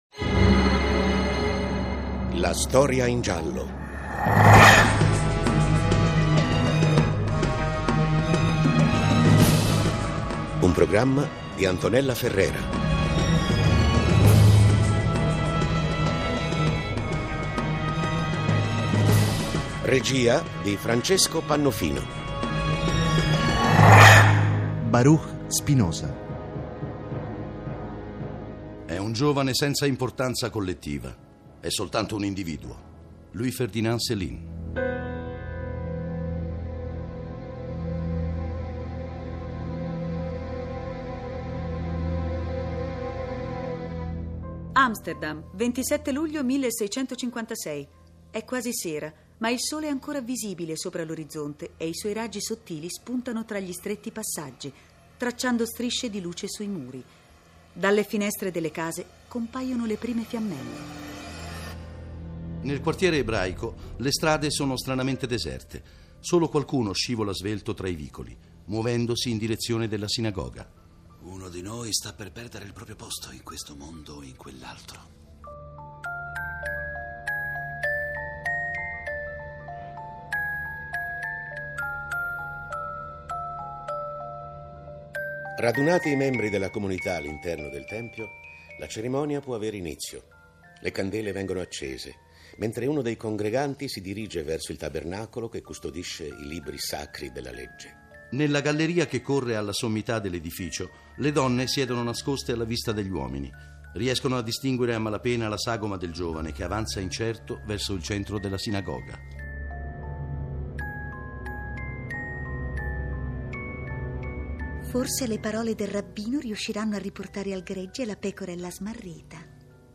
La voce di Baruch Spinoza e' quella di Riccardo Rossi .